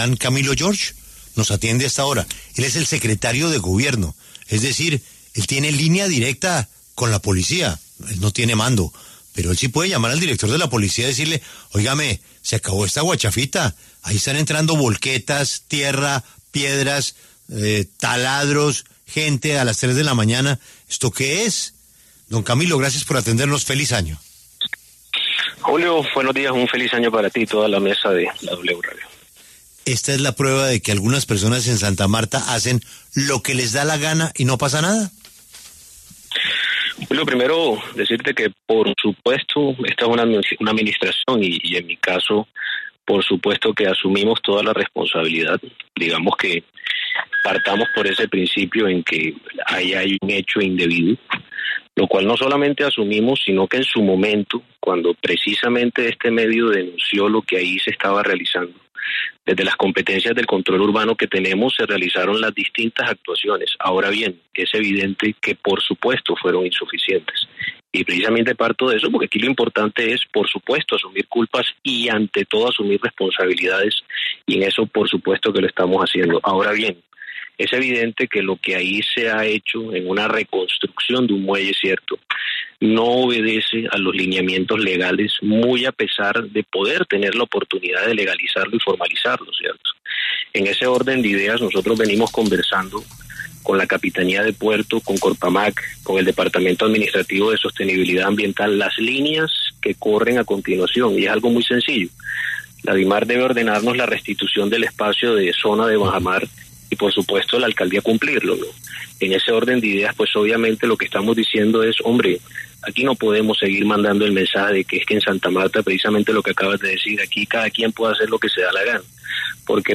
En diálogo con La W, el secretario de Gobierno, Camilo George, se refirió a dicha obra que se estaría haciendo clandestinamente en Santa Marta, afirmando que desde su administración se acepta la responsabilidad.